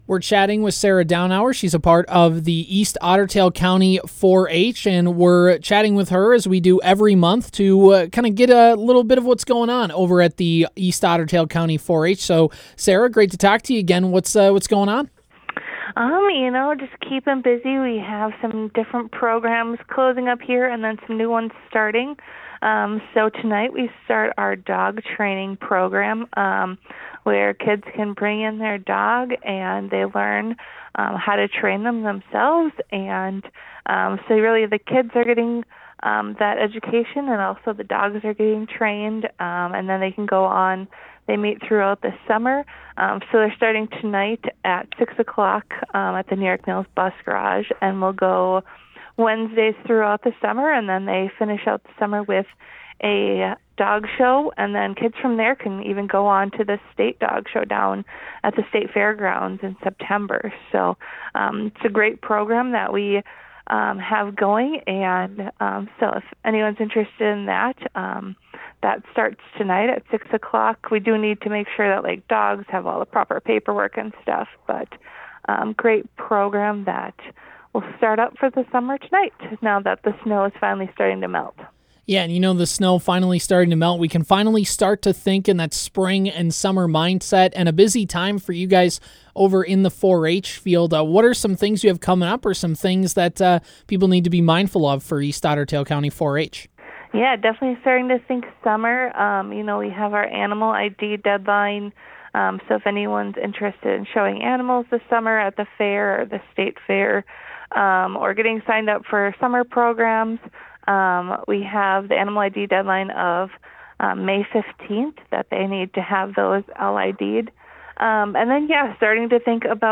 stopped in studio to talk about what’s going on during the month of April.